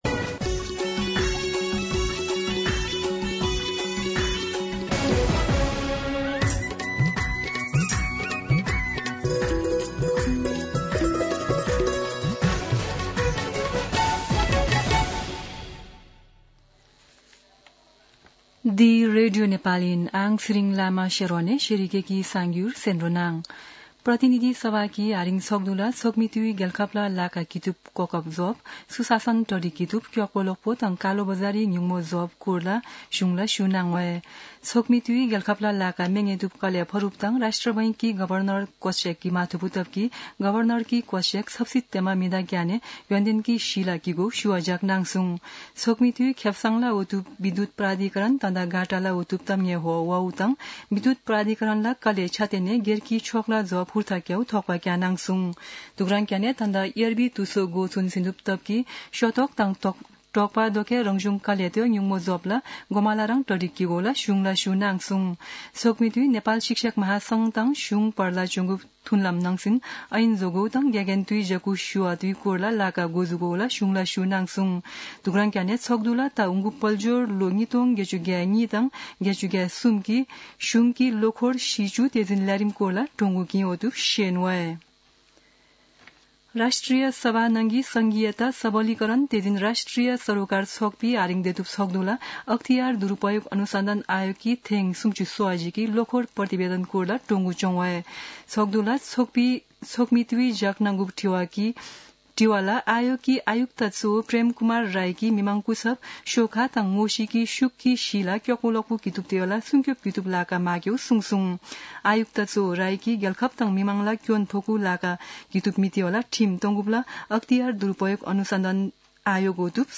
शेर्पा भाषाको समाचार : २४ वैशाख , २०८२
Sherpa-News-01-24.mp3